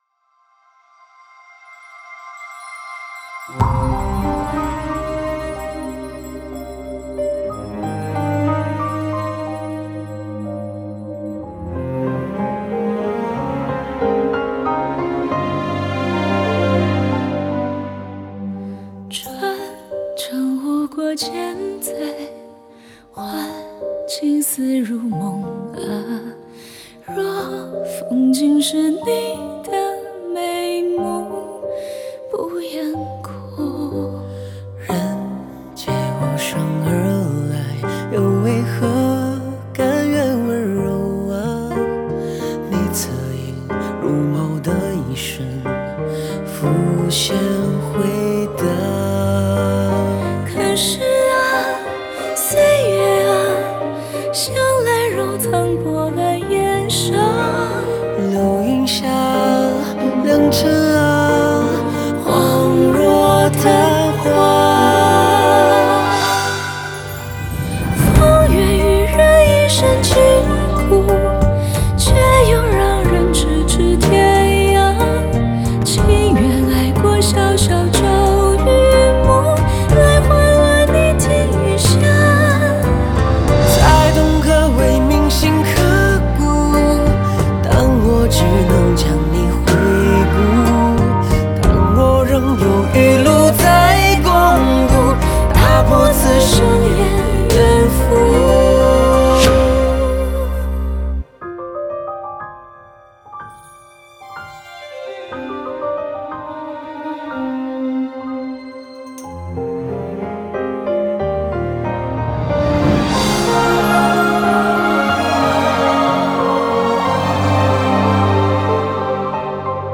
Ps：在线试听为压缩音质节选，体验无损音质请下载完整版
影视剧主题曲